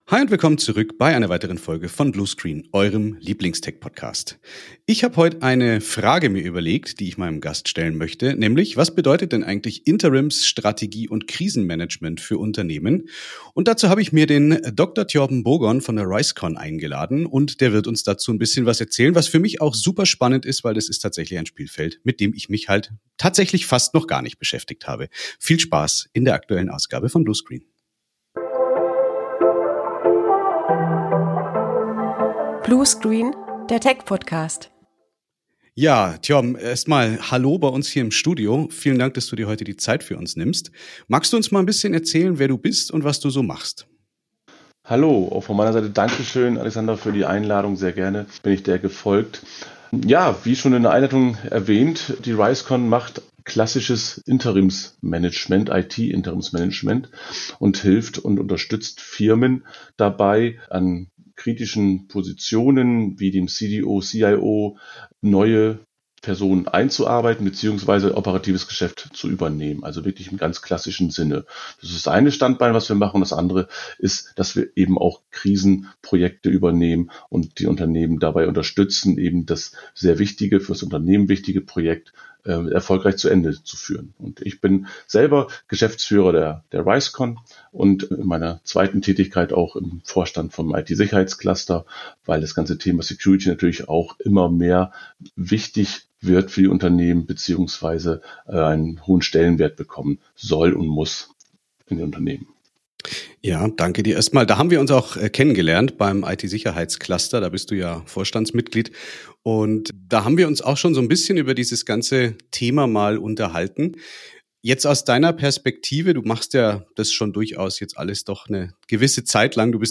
Die Themen sind in erster Linie technischer Natur, wir haben auch immer wieder Gäste aus der Wirtschaft und Technologie-Anbieter im Interview.